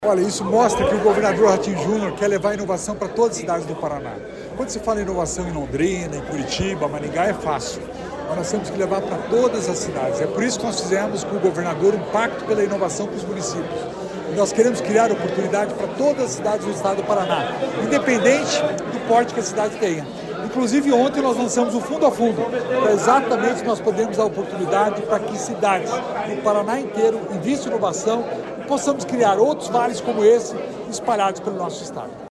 Sonora do secretário da Inovação e Inteligência Artificial, Alex Canziani, sobre a Parceria Paraná-Coreia do Sul